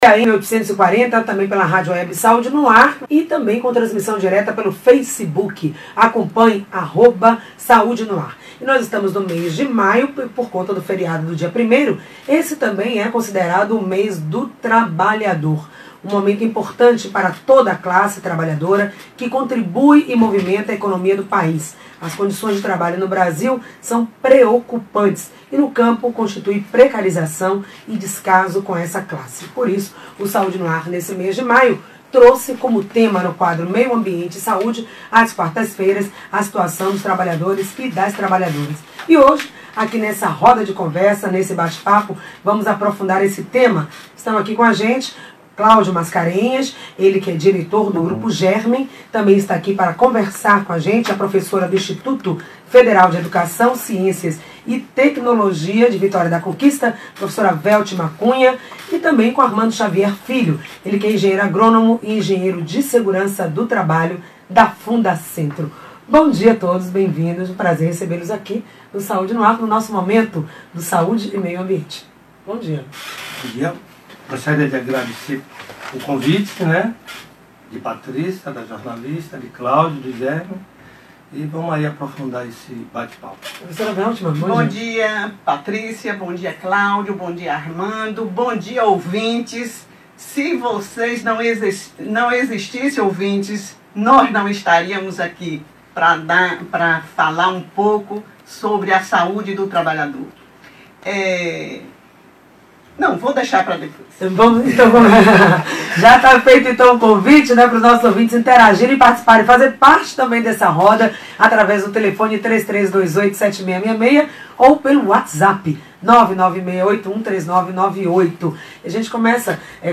Roda de conversa:saúde no Trabalhador -